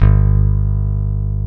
EPM E-BASS A.wav